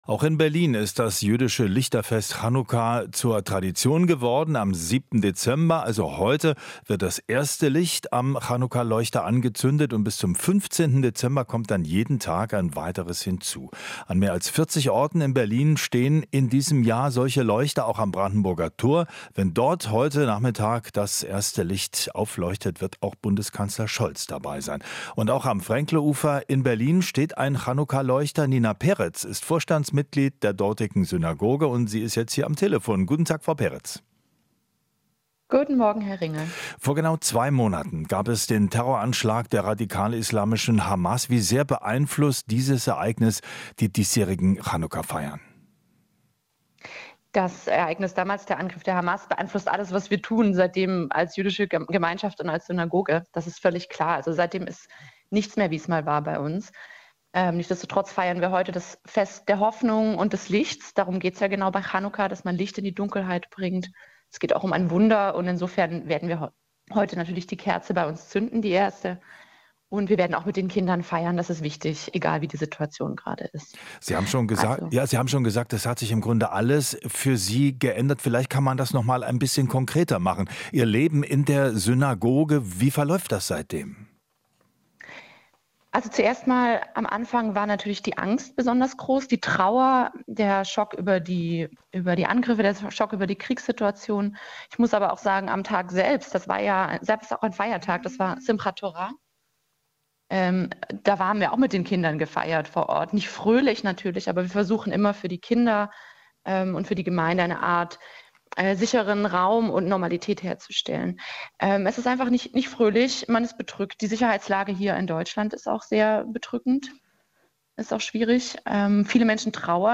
Interview - Chanukka in Berlin: Lichterfest feiern in bedrohlichen Zeiten